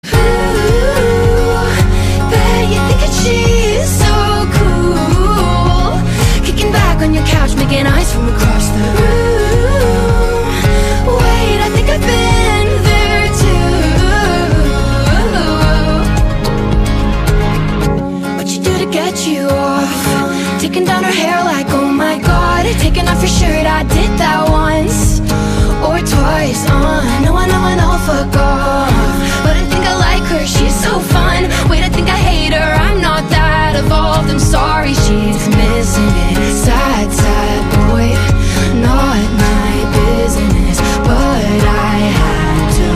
Kategorien: POP